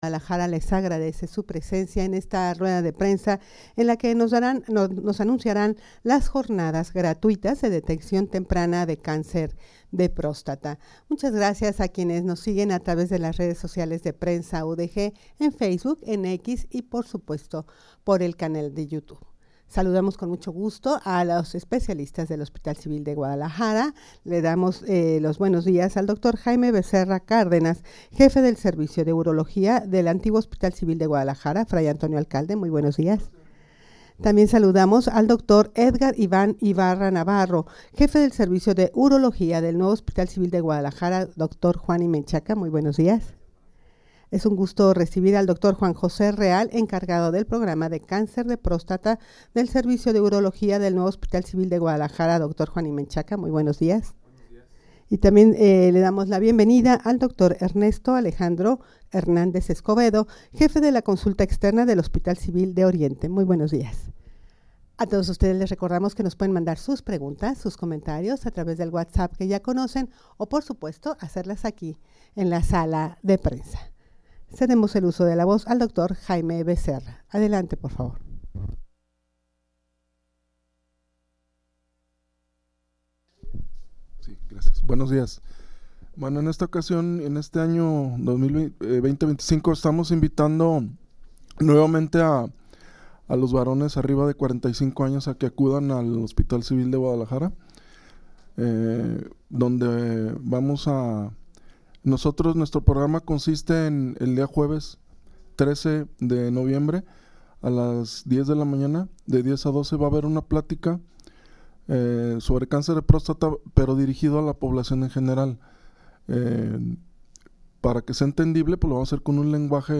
Audio de la Rueda de Prensa
rueda-de-prensa-para-anunciar-las-jornadas-gratuitas-de-deteccion-temprana-de-cancer-de-prostata.mp3